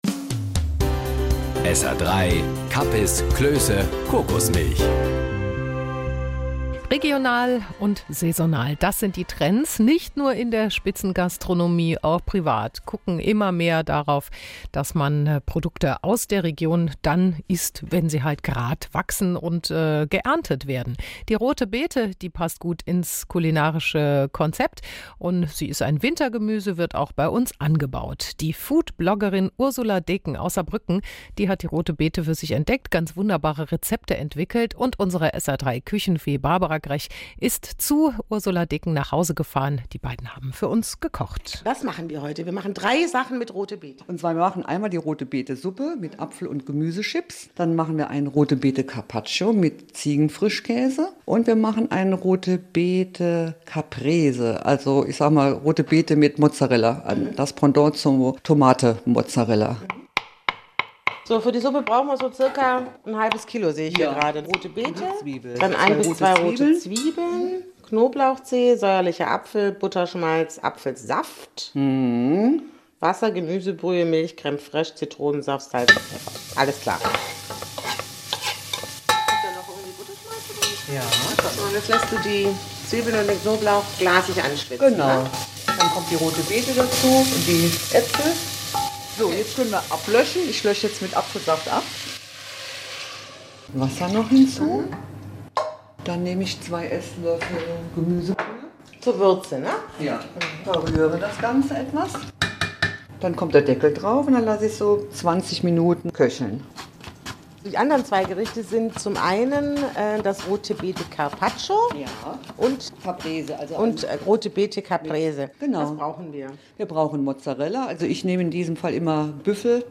Zu hören war es am Samstag, 07.01.2017 auf SR 3 Saarlandwelle in der Sendung Bunte Funkminuten, ein Service-Magazin am Vormittag im Saarland.